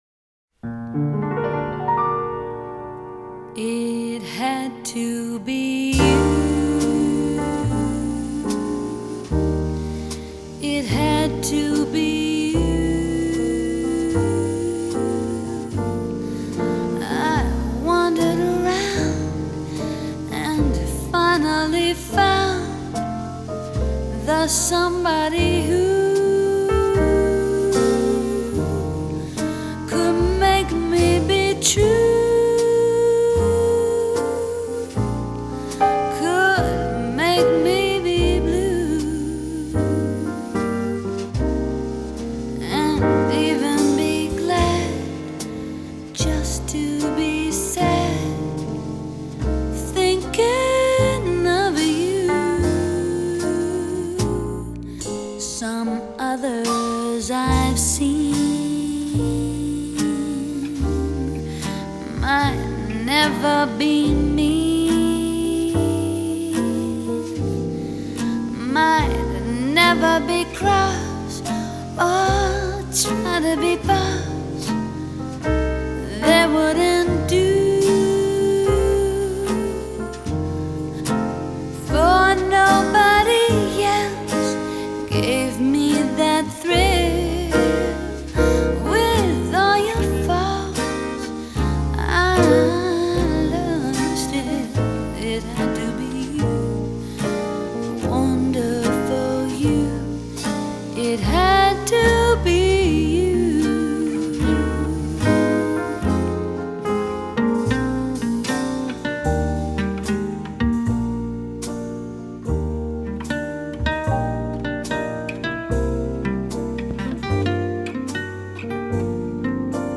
勾人心魂的細膩歌聲 墜入爵士流行的無限遐思；帶點慵懶、加點灑脫、和著些許放肆、煽情，再來點幻想…
擅於抒發都會男女情愫的性感女聲
在鋼琴、吉它、薩克斯風等樂器的相襯下
谢谢分享这别致的女声